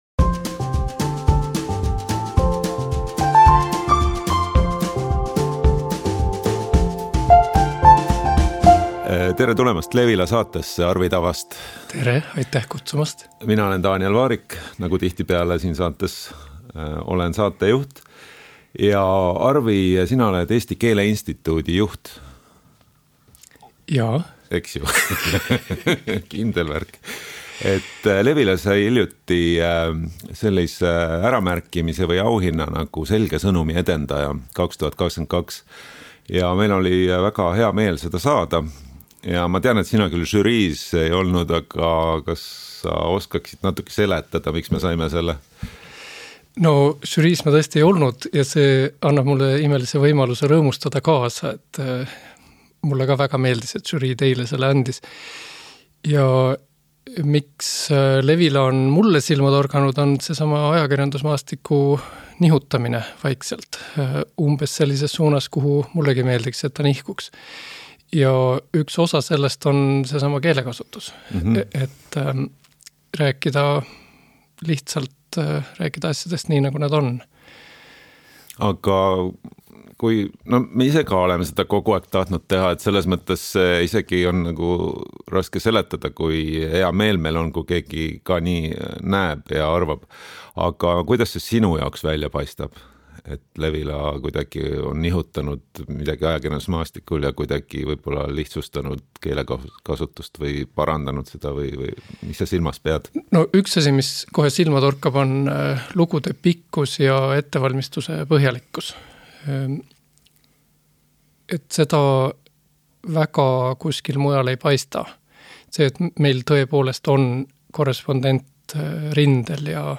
vestlevad sellest, kuidas keeruline jutt ja võimul olemine omavahel seotud on.